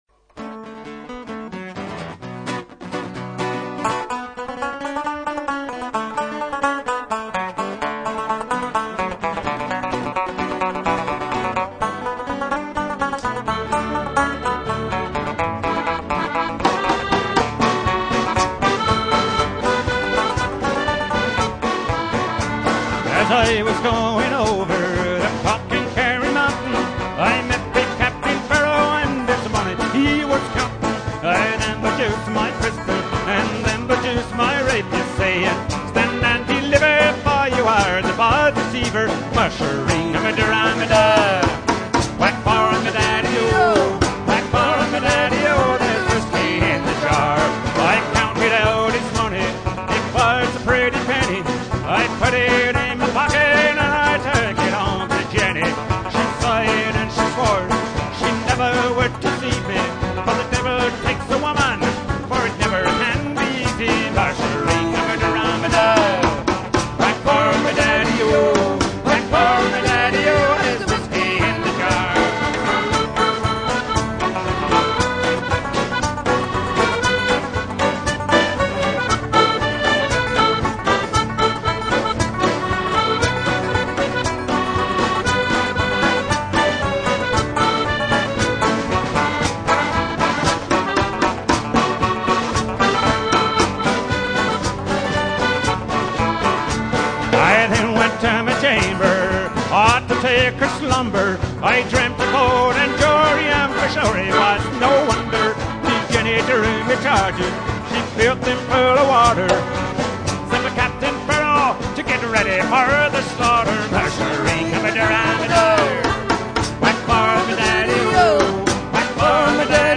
For Irish /Celtic Nights the line up is vocals, guitar, bass, fiddle, mandolin, accordion, mouth organ & our expert percussionist plays both bodhran & conventional drums. We play a mixture of romantic ballads, foot tapping party songs & some driving jigs & reels.